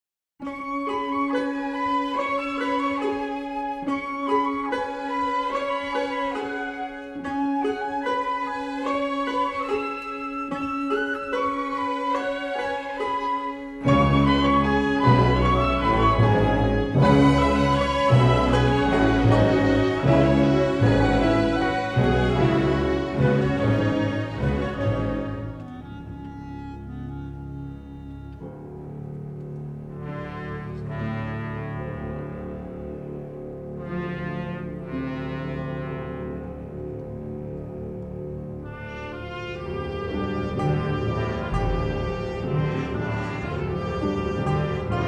each filled with exotic melody and instrumentation.
with saxophone, harpsichord and flute elegantly spotlighted.
remixed in stereo from the original three-track masters.